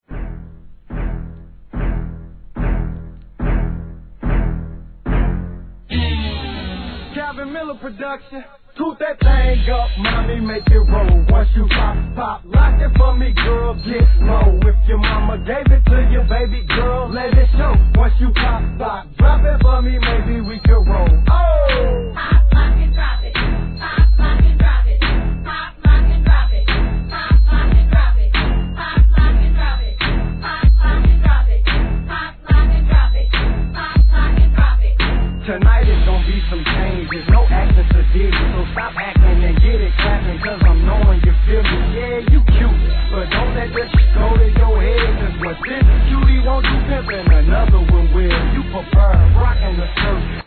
HIP HOP/R&B
ゆったりとした電子音がダークな今風バウンスビートに落ち着いたフロウで聴かせます。